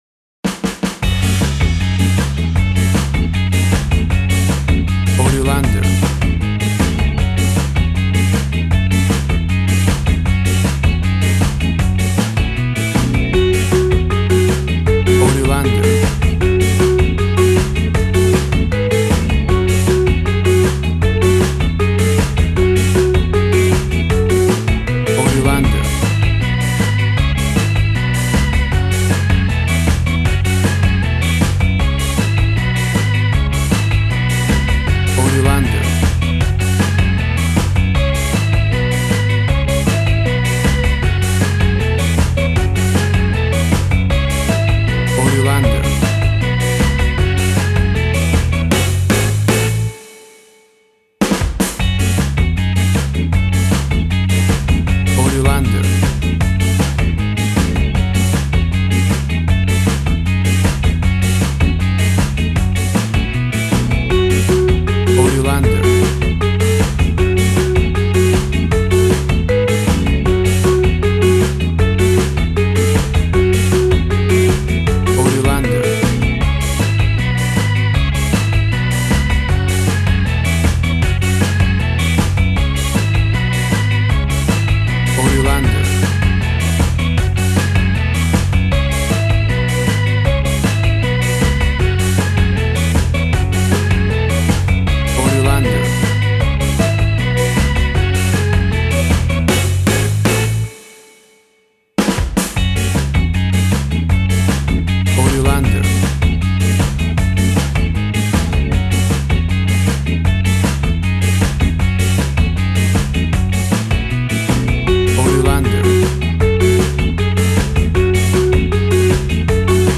Classic reggae music with that skank bounce reggae feeling.
WAV Sample Rate 16-Bit Stereo, 44.1 kHz
Tempo (BPM) 156